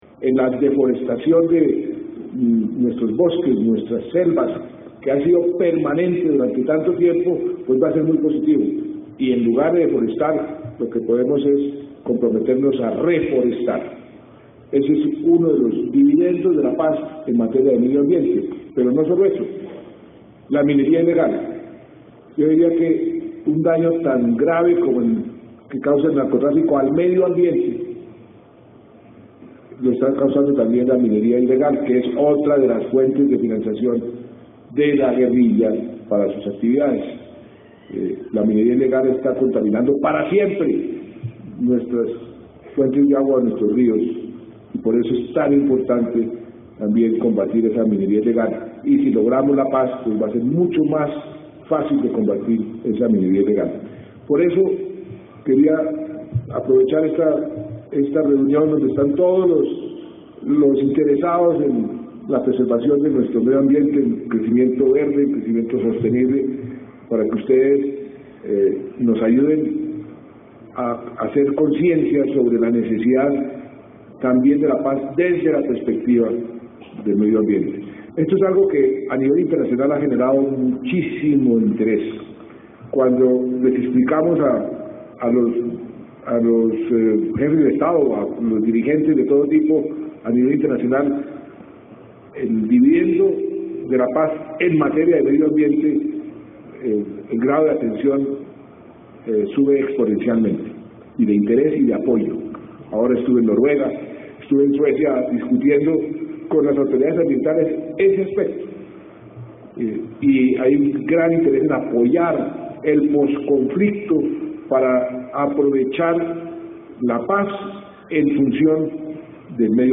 En el Primer encuentro de articulación por el Crecimiento Verde
Declaraciones del Presidente de la República, Juan Manuel Santos